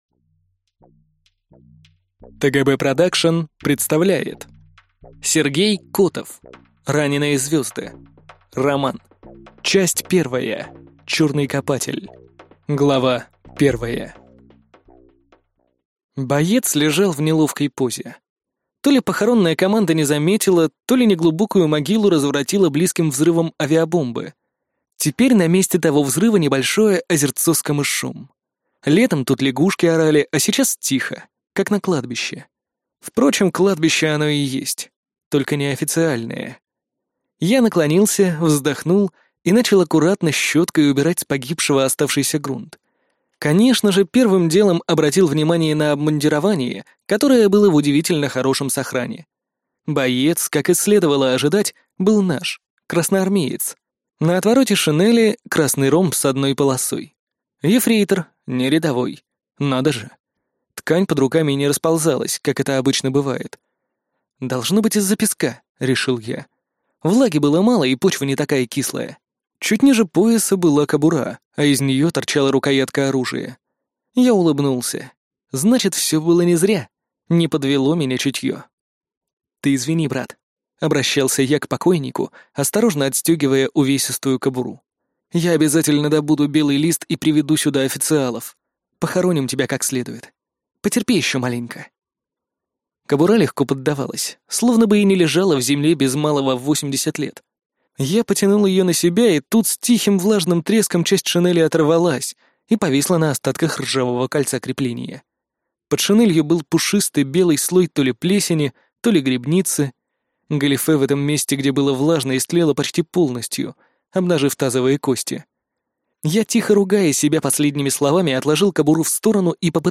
Аудиокнига Раненые звёзды | Библиотека аудиокниг